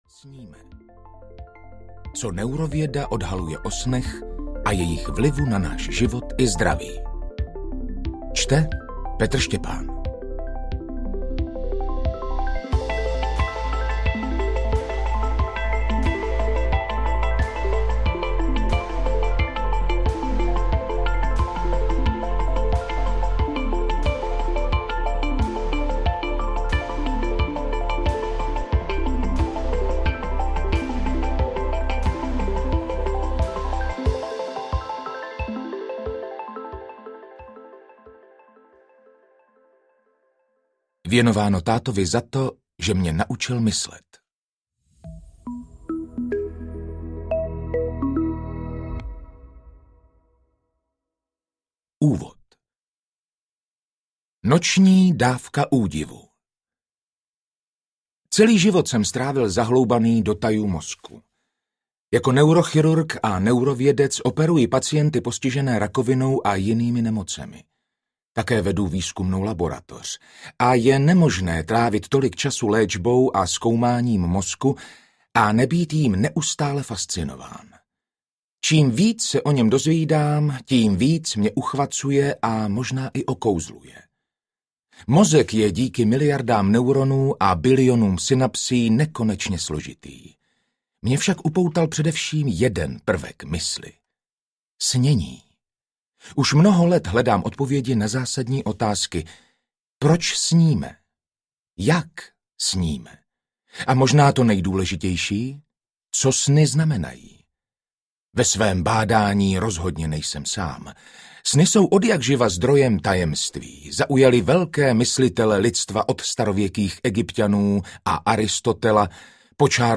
Proč sníme audiokniha
Ukázka z knihy